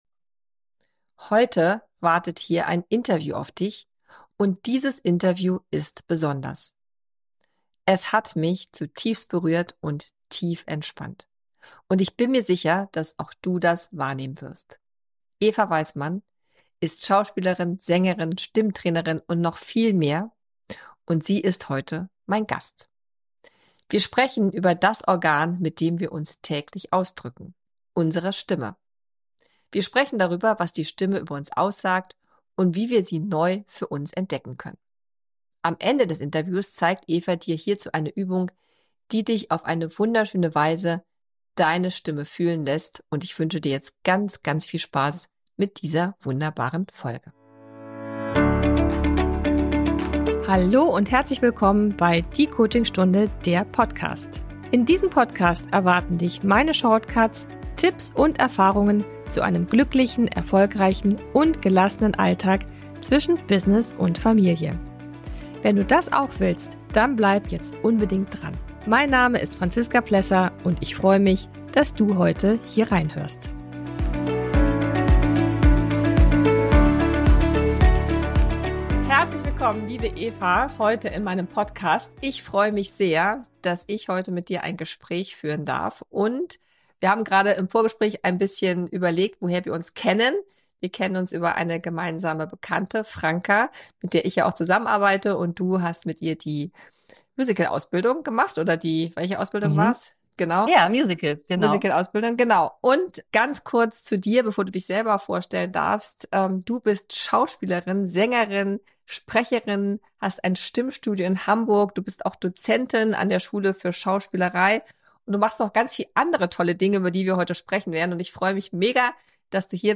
Dieses Interview ist besonders.